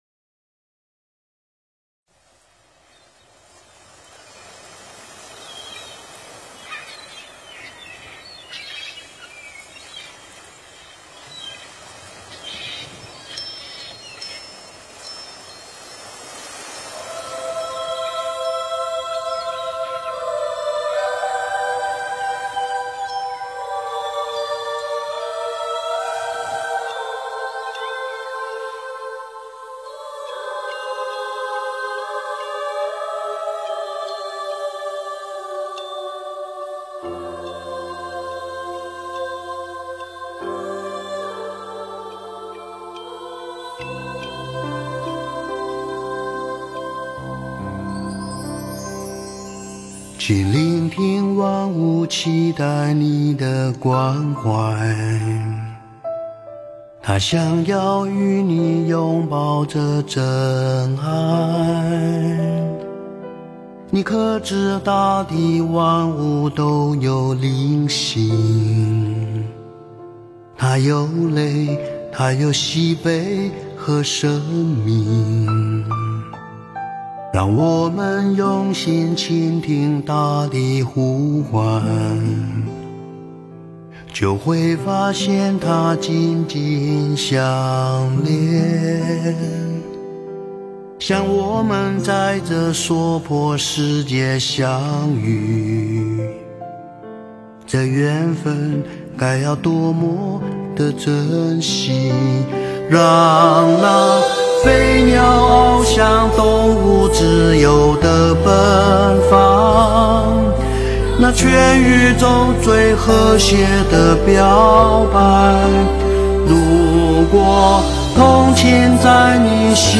标签: 佛音诵经佛教音乐